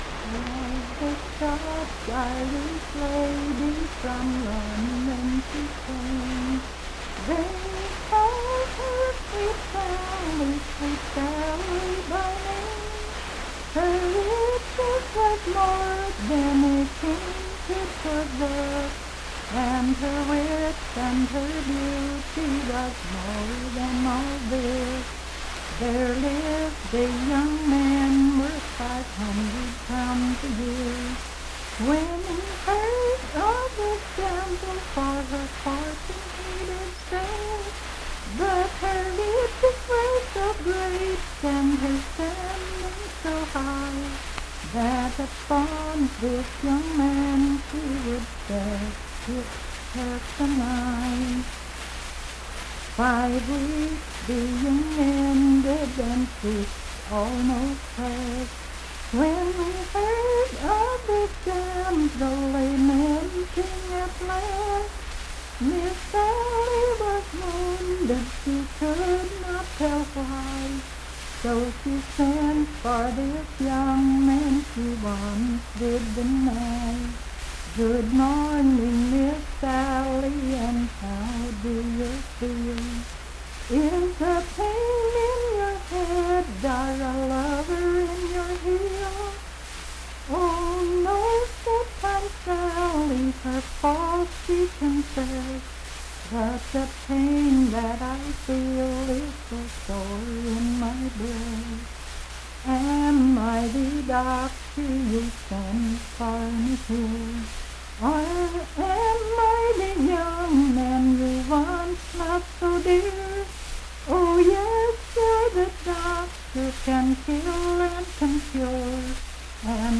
Unaccompanied vocal music
Verse-refrain 9(4).
Voice (sung)
Mineral County (W. Va.)